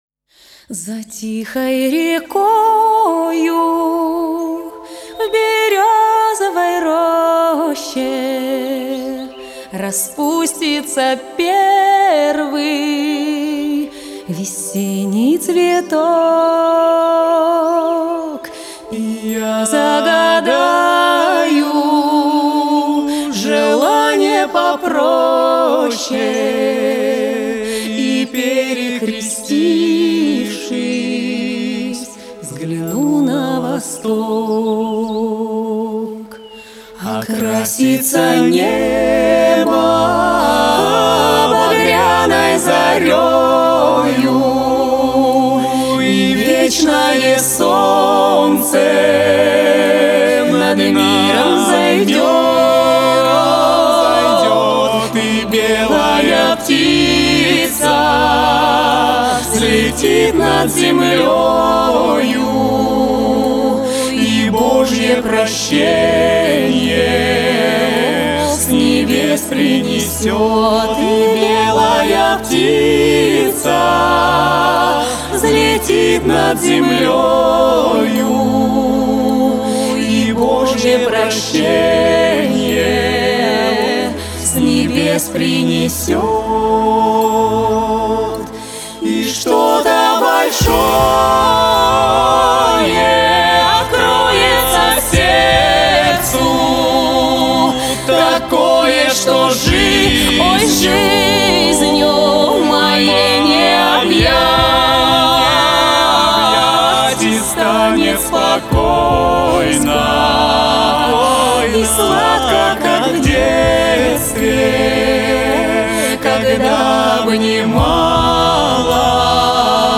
• Категория: Детские песни
фолк, народный мотив